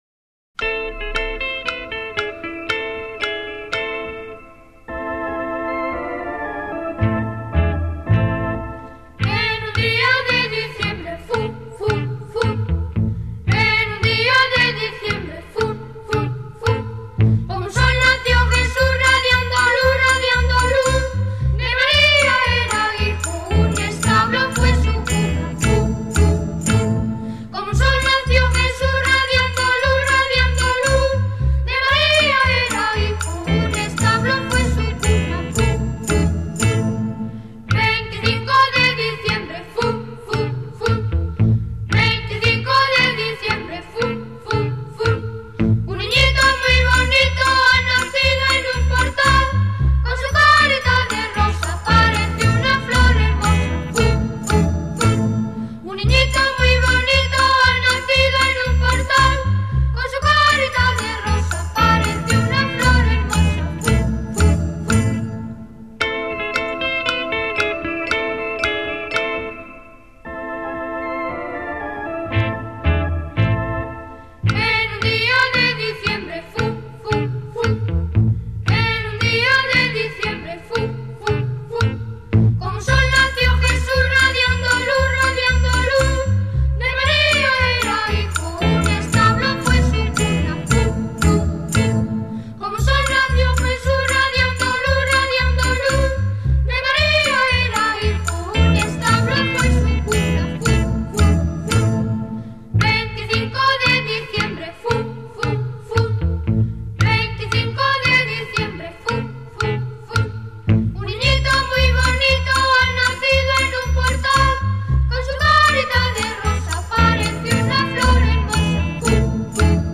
Villancico/ Carol